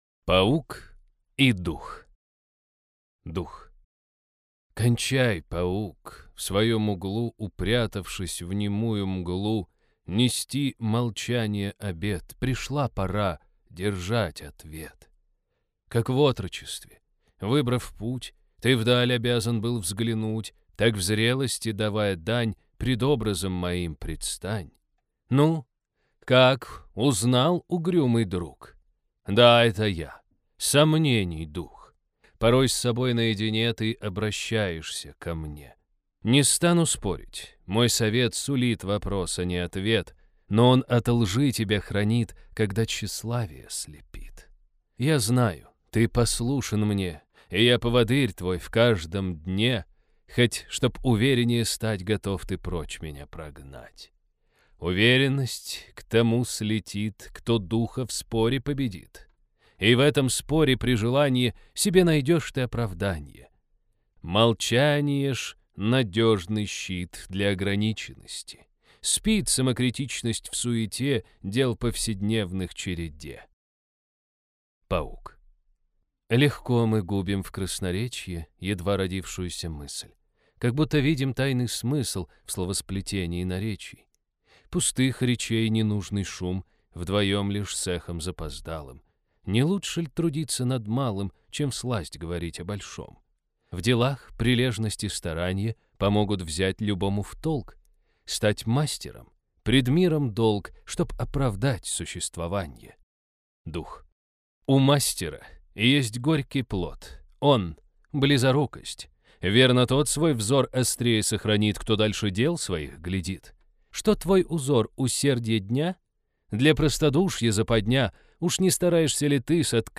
Аудиокнига Паук и дух | Библиотека аудиокниг